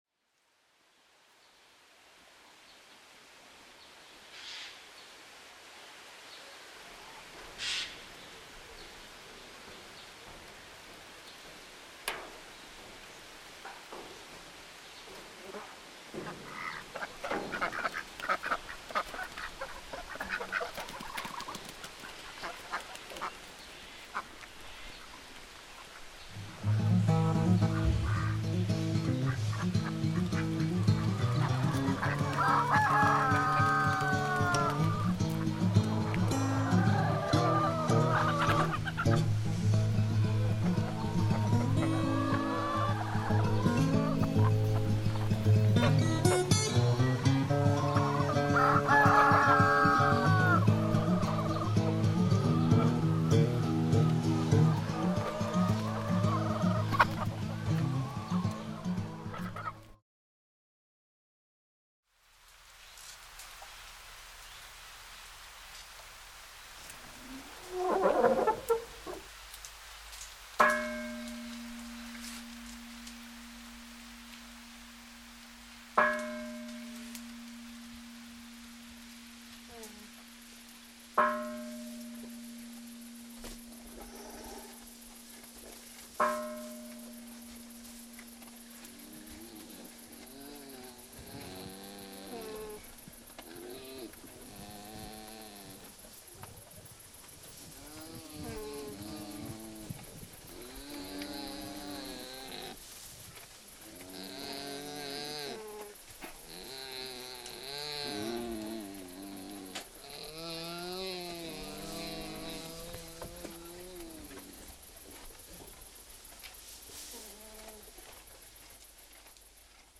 Erstmals öffentlich zu hören: akustische Momentaufnahmen aus den 13 Ortsteilen der Fontanestadt Neuruppin, zusammengemixt zu einer Viertelstunde Heimatklänge
Audiowalk
Neuruppin_Mix_13Orte.mp3